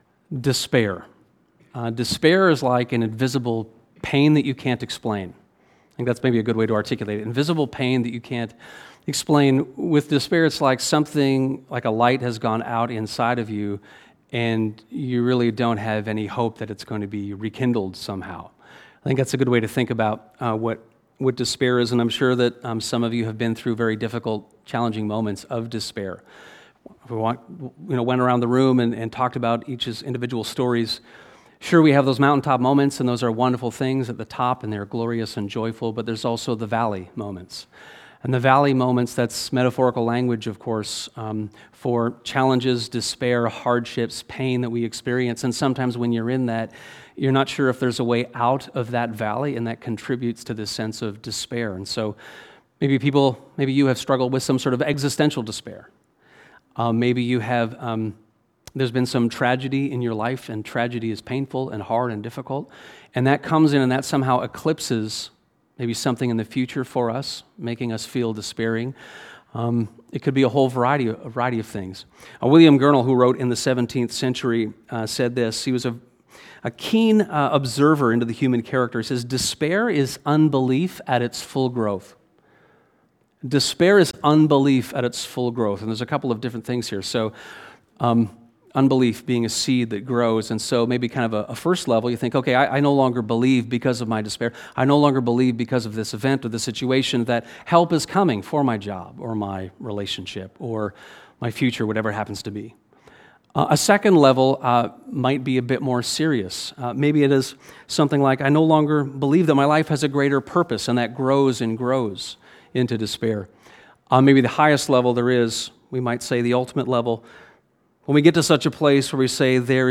Sermons | Westminster
This Easter sermon explores Mark 16:1-8 and invites you into the story of a corpse-raising God when it comes to our own apparent impossibilities.